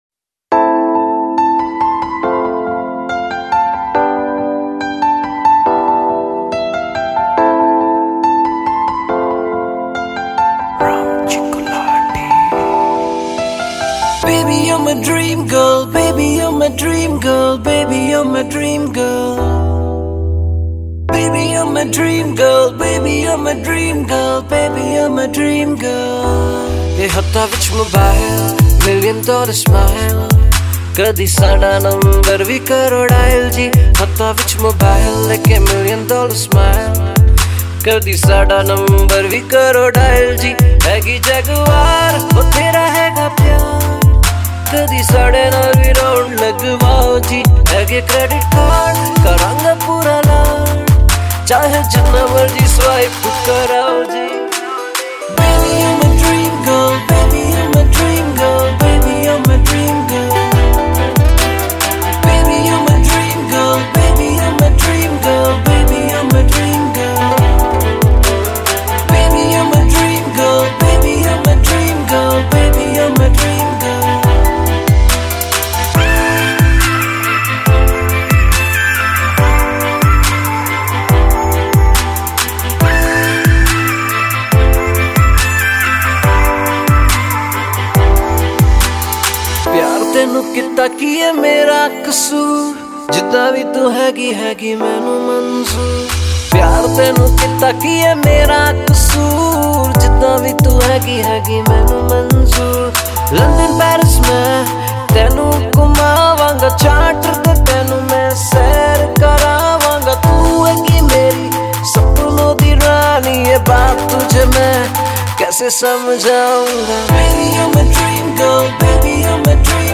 Pop Songs
Indian Pop